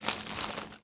R - Foley 70.wav